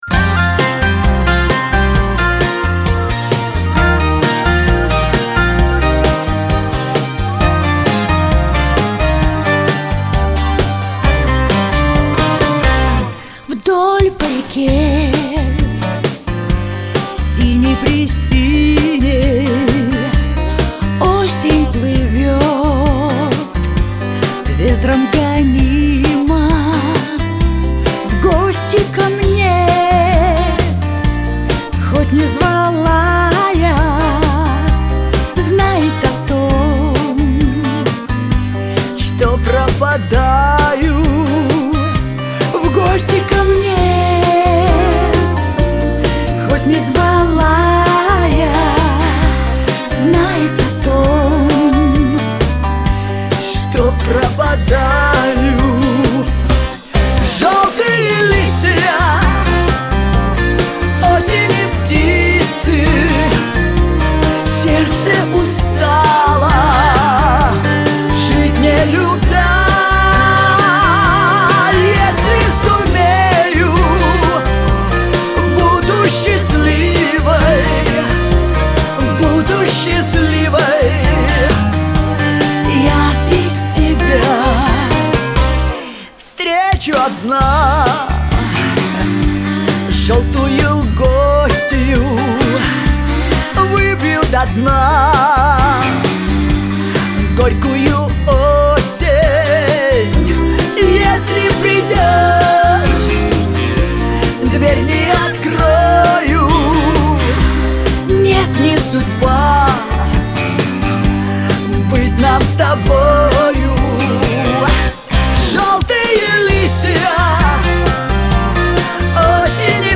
Жанр: Pop, Russian Pop